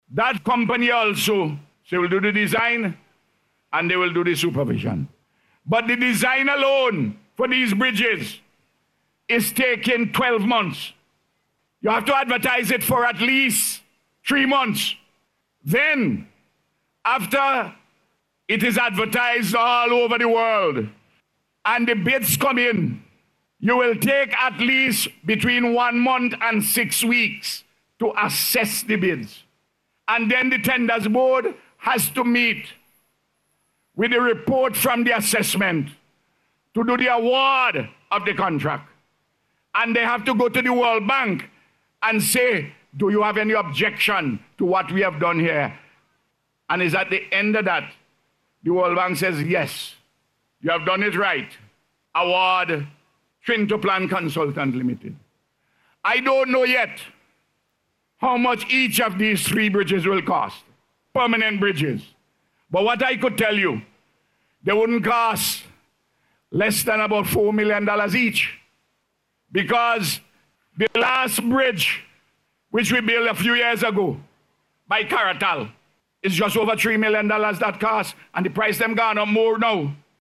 This was disclosed by Prime Minister Dr. Ralph Gonsalves as he delivered the feature address at the official opening of the Bridges yesterday.